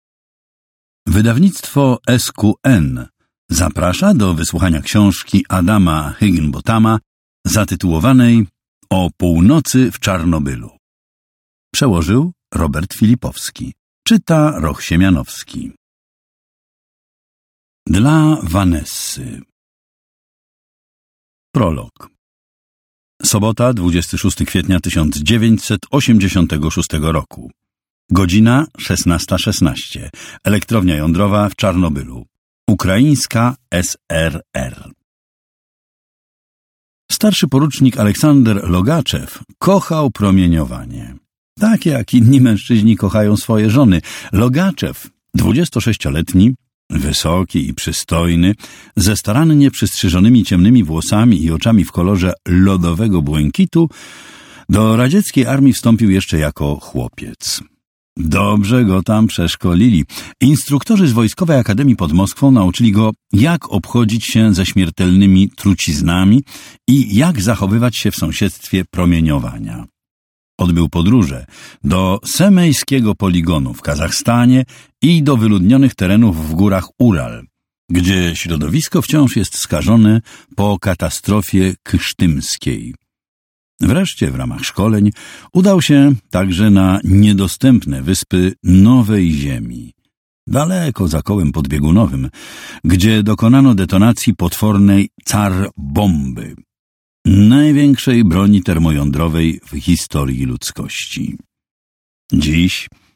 Kup audiobook: O północy w Czarnobylu. Nieznana prawda o największej nuklearnej katastrofie.